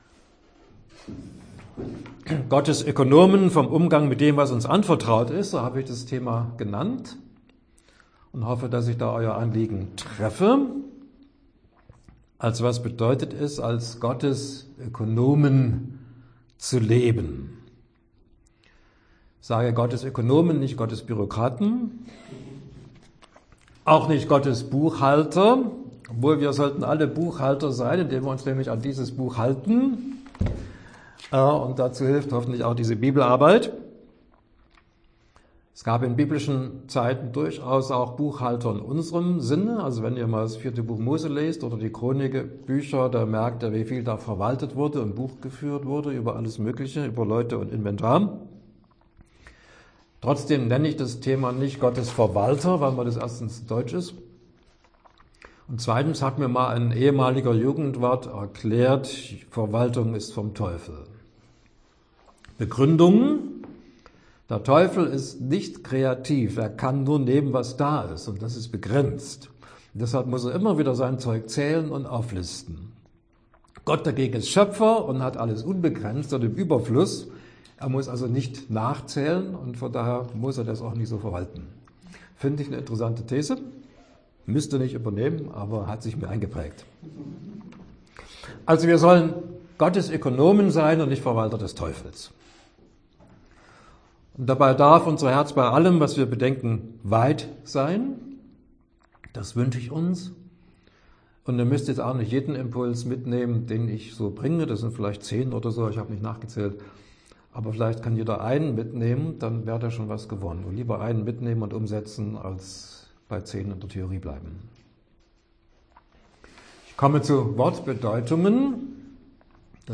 Gottes Ökonomen – Bibelarbeit
Dauer des Vortrags: ca. 64 Minuten